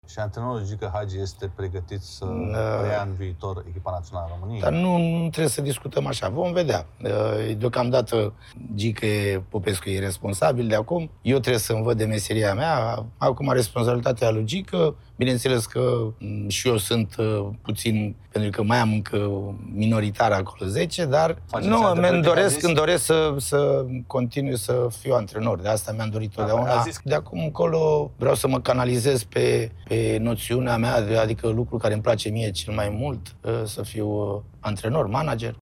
Gică Hagi reacționează după ce a cedat statutul de acționar majoritar la Farul Constanța „Vreau să mă întorc la antrenorat… și Naționala este obiectivul oricărui fotbalist”, sunt declarațiile sale într-un interviu pentru ProSport.